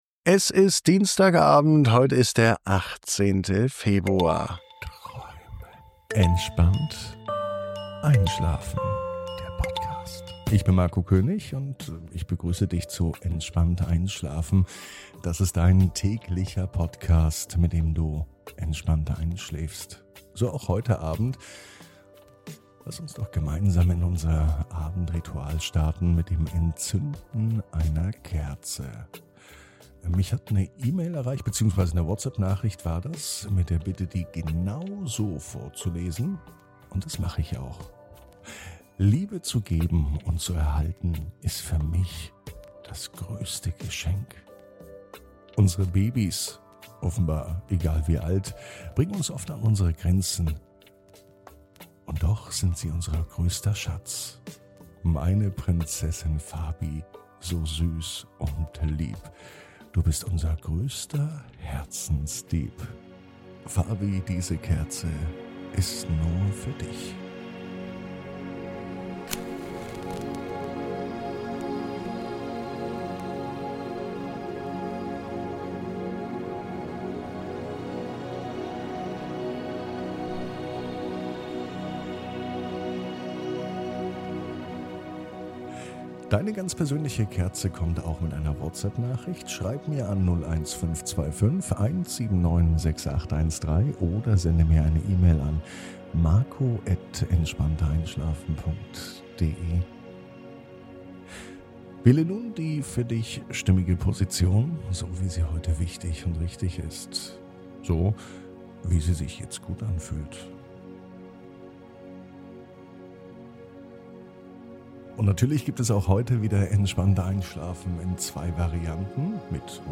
1 (ohne Musik) Di, 18.02.. Entspannt einschlafen - Traumreise Wo die Sonne durch die Blätter tanzt 19:57